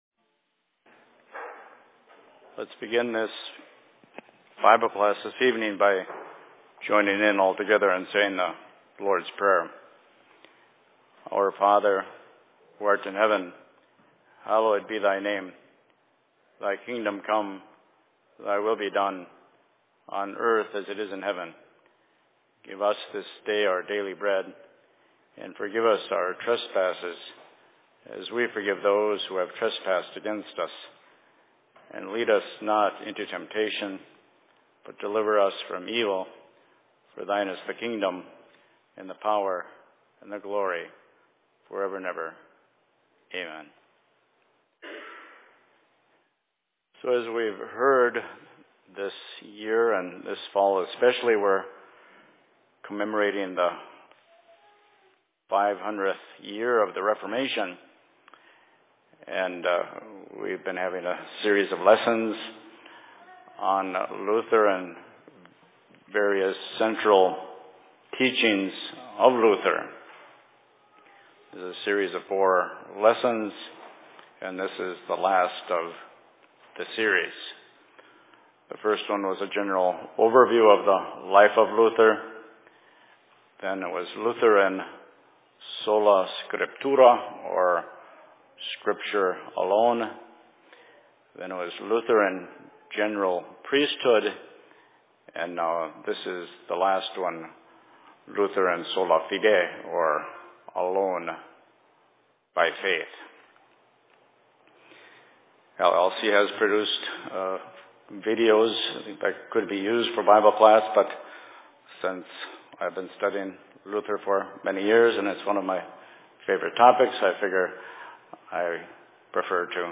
Bible Class in Minneapolis 29.11.2017
Location: LLC Minneapolis